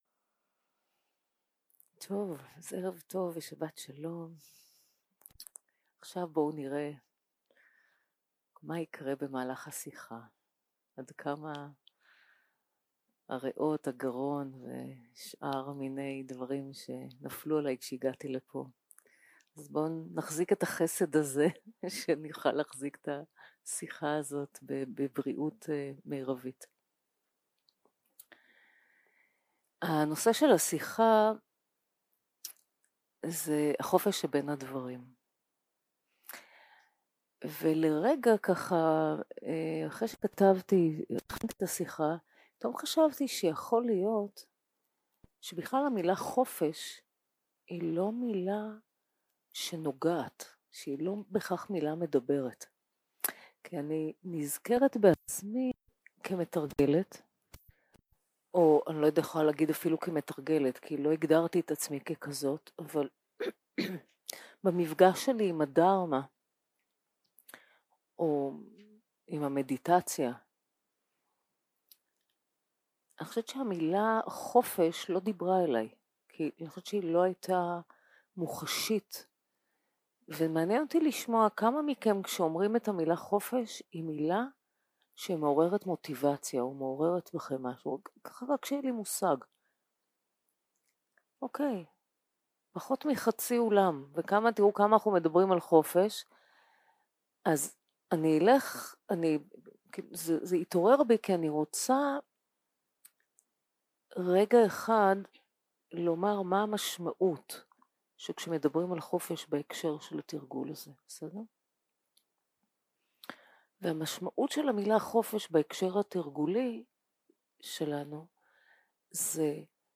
יום 3 - הקלטה 7 - ערב - שיחת דהרמה - החופש שבין הדברים
סוג ההקלטה: שיחות דהרמה